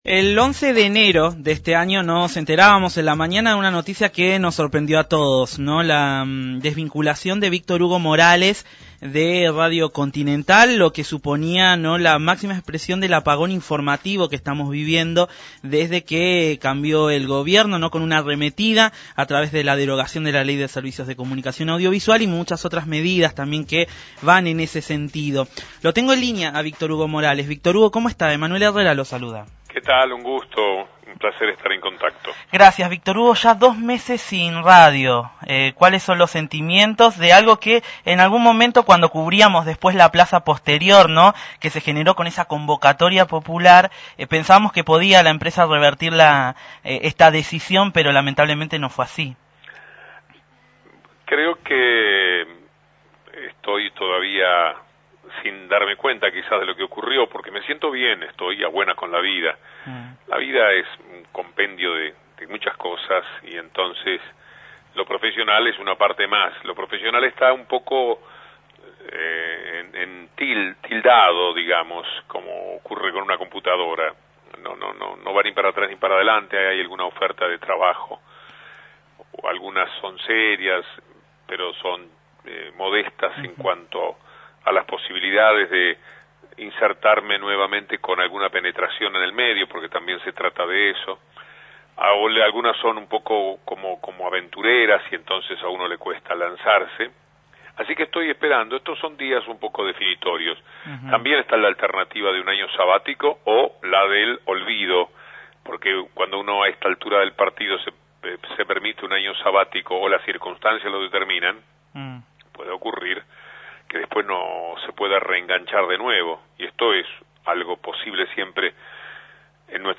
A dos meses de su salida de Radio Continental, el locutor, periodista y escritor uruguayo Víctor Hugo Morales, habló en Nube Sonora.